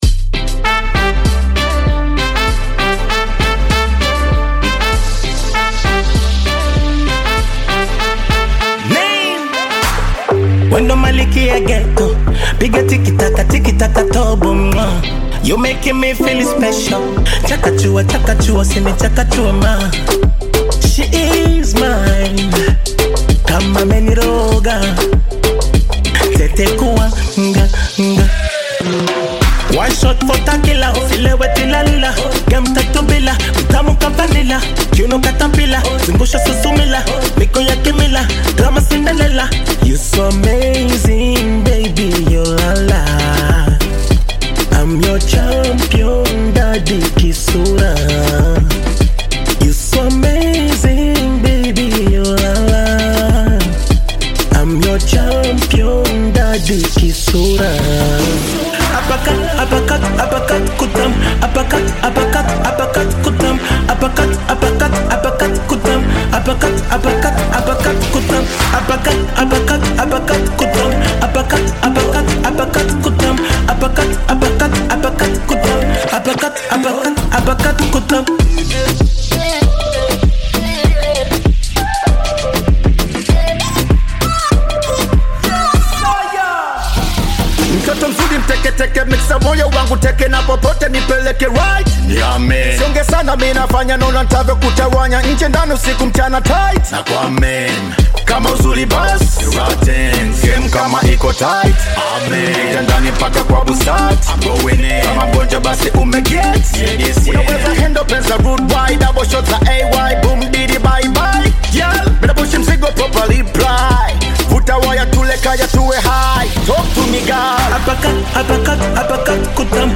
Tanzanian bongo flava artist, singer and songwriter
African Music